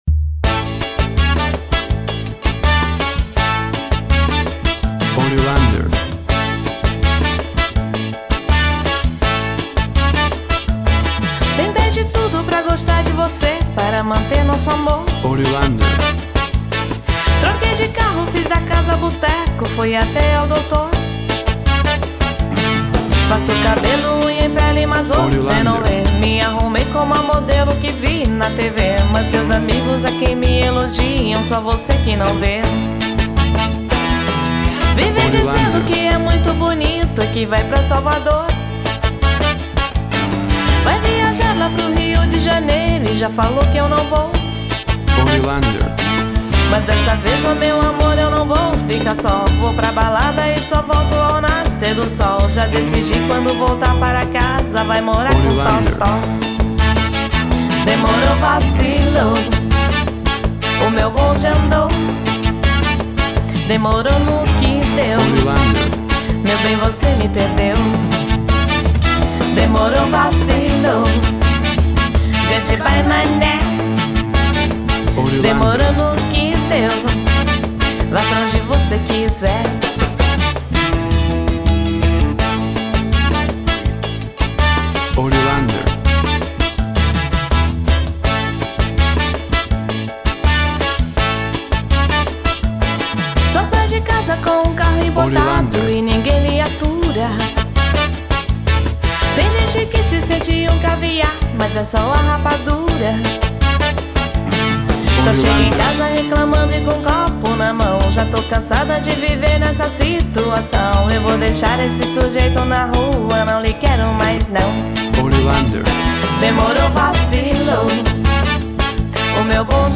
Samba Rock, brazilian rhythm.
Tempo (BPM) 82